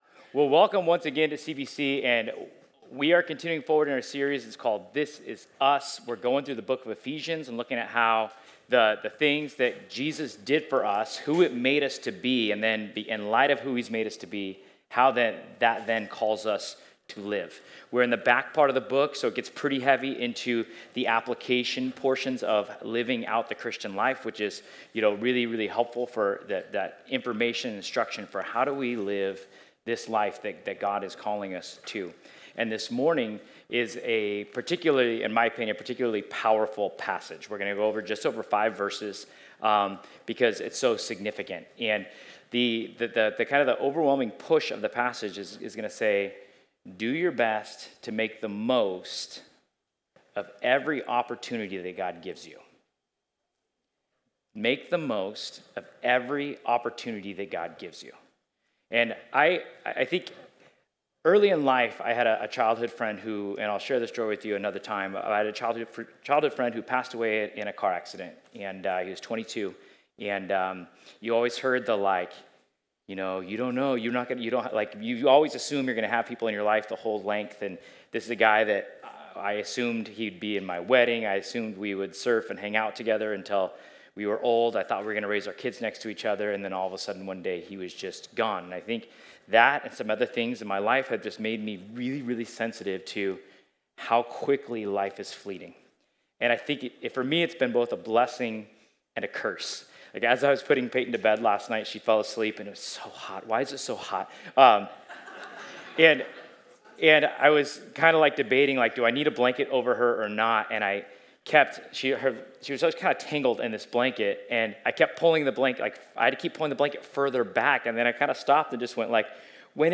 Ephesians 5:15-20 Service Type: Sunday In the infamous words of Ferris Bueller